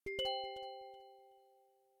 ve_abstracttiles_unlock.ogg